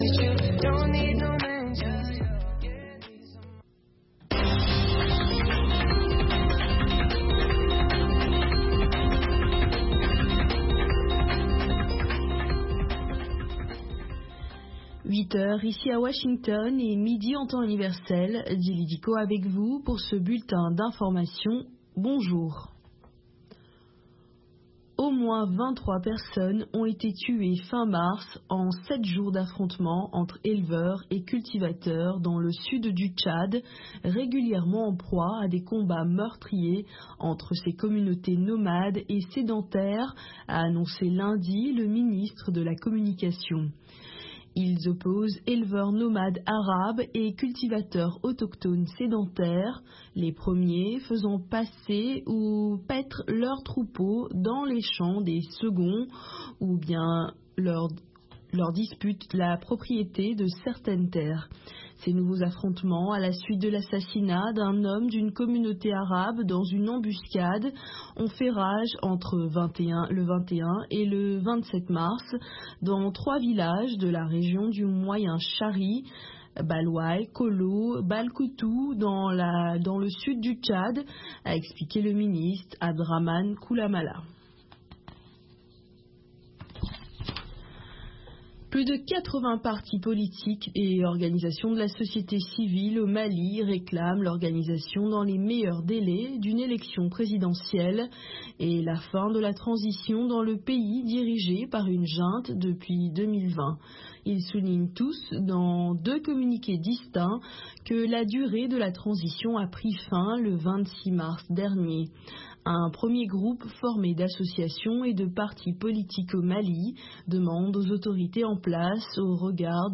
Bulletin d'information de 16 heures